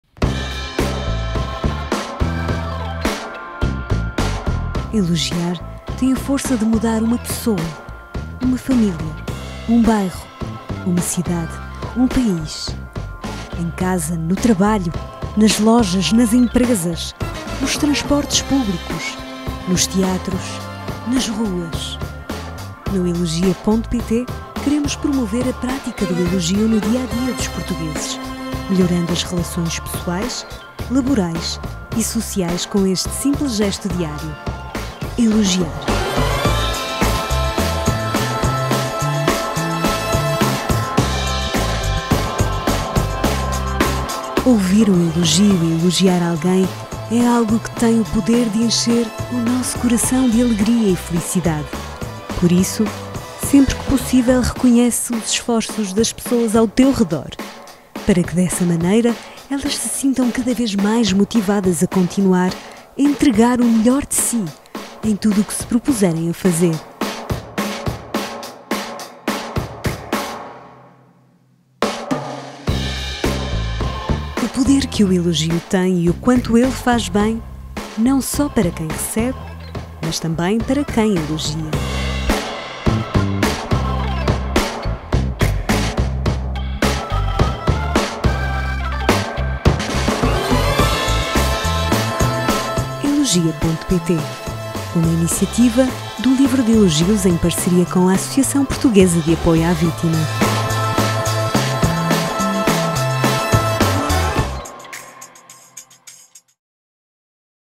Portuguese Female Voiceover
Corporate Video